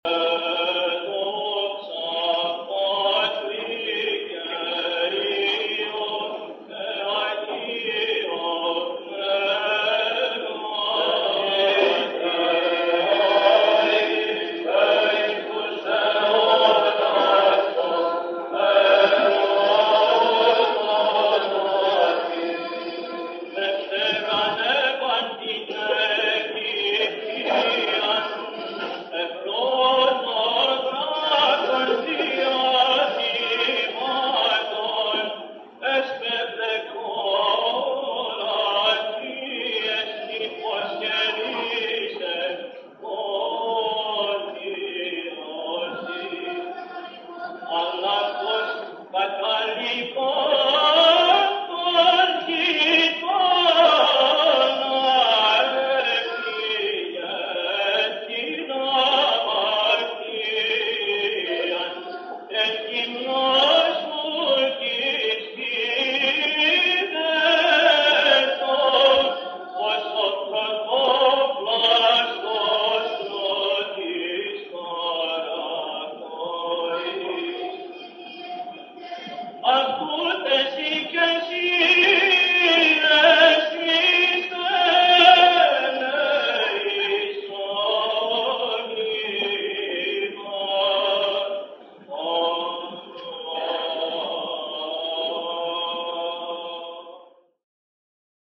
Δοξαστικόν
(ἠχογρ. Κυρ. Βαΐων ἑσπέρας)